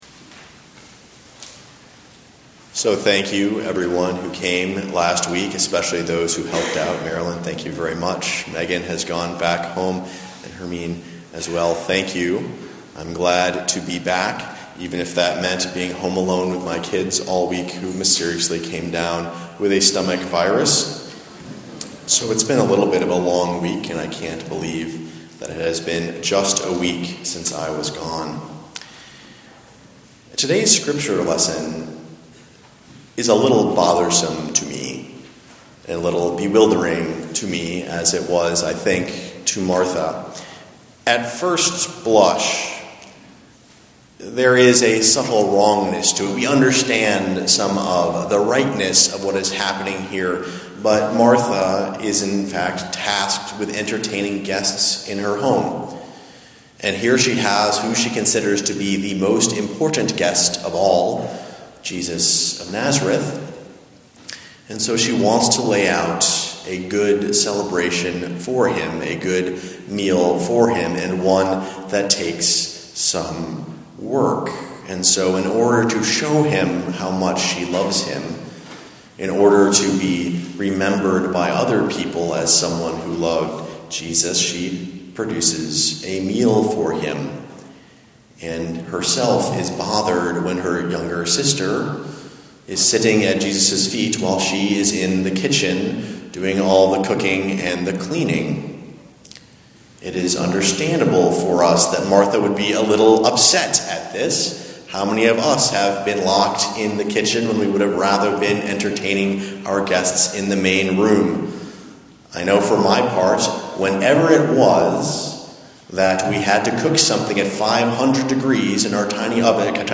Preached July 21, 2013 at the Congregational Church UCC in Iowa City. Sermon text: Luke 10:38-42.